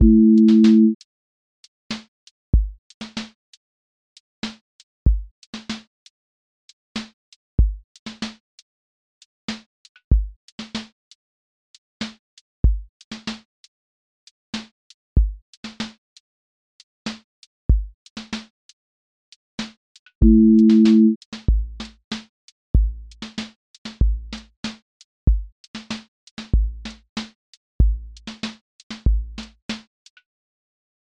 r&b pocket with warm chord bed
• voice_kick_808
• voice_snare_boom_bap
• voice_hat_rimshot
• voice_sub_pulse
• tone_warm_body